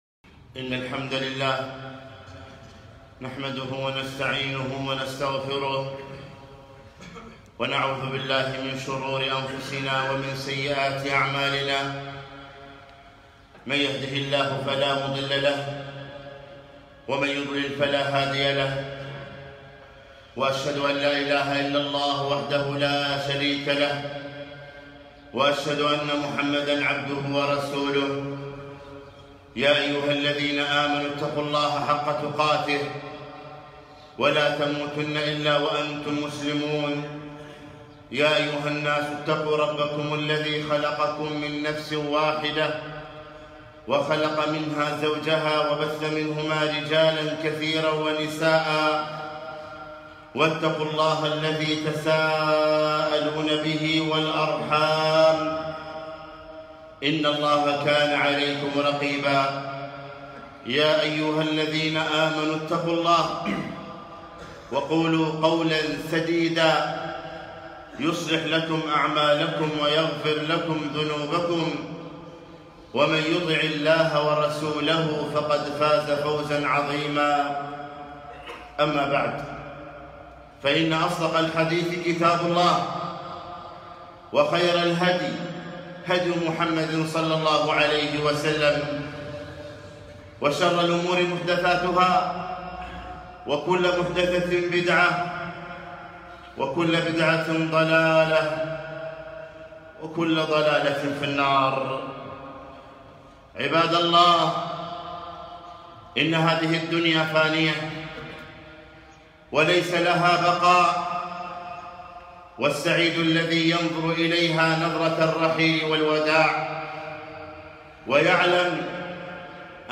خطبة - هادم اللذات ومفرق الجماعات - دروس الكويت